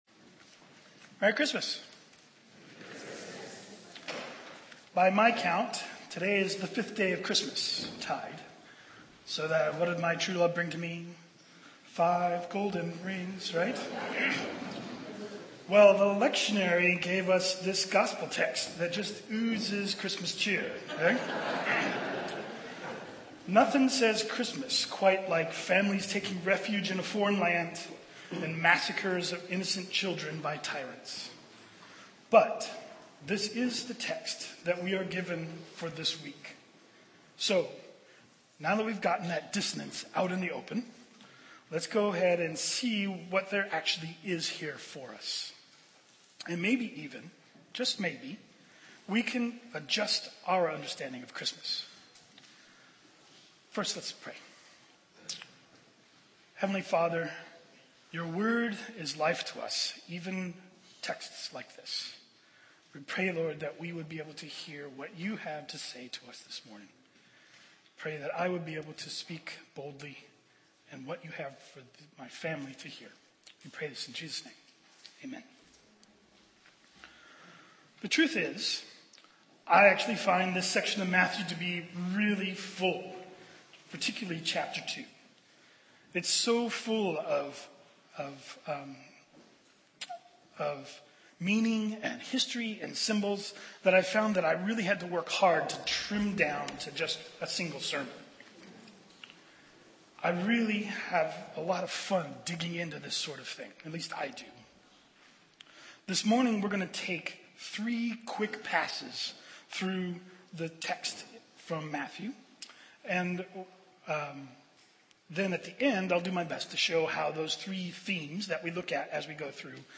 I have really had to work hard at trimming to get down to a single sermon.